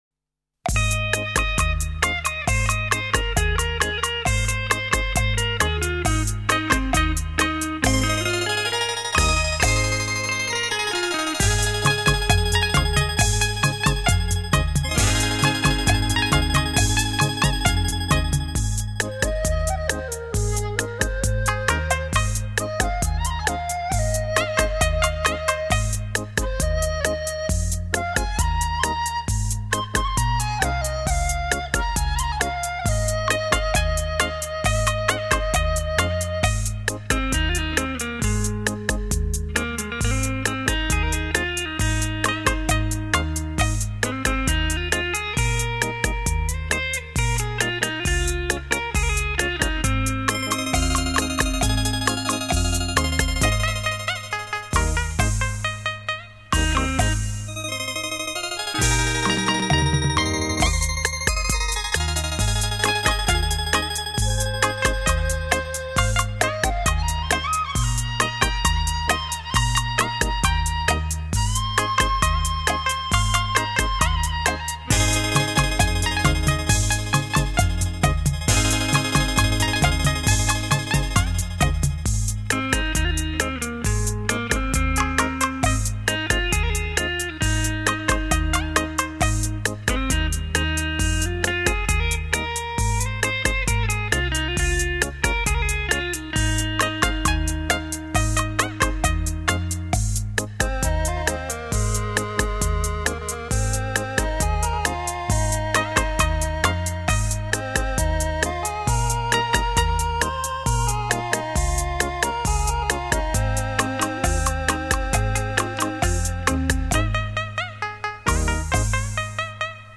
专辑类型：电子琴音乐
超立体现场演奏
曲曲精選 曲曲動聽，电子琴音域较宽，和声丰富，
发音音量可以自由调节，甚至可以演奏出一个管弦乐队的效果，
另外，电子琴还安装有混响回声，延长音、震音和颤音等
精心打造完美电子音乐，立體效果 環繞身歷聲 超魅力出擊，